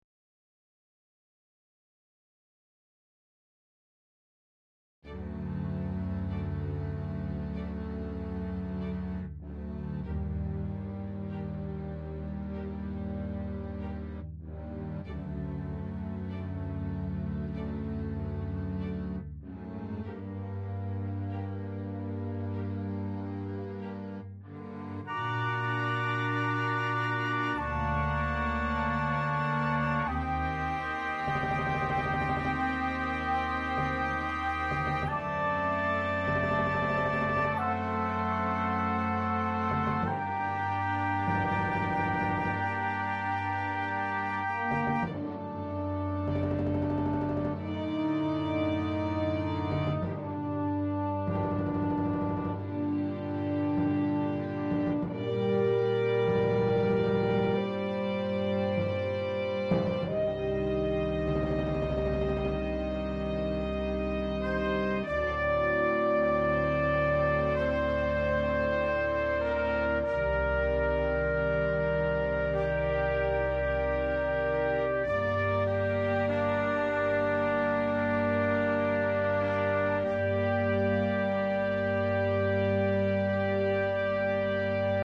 J'ai repris avec une version midi sur laquelle j'ai mis tous les instruments à zéro, puis j'ai positionné seulement dans logic, sans réverbe ni compression.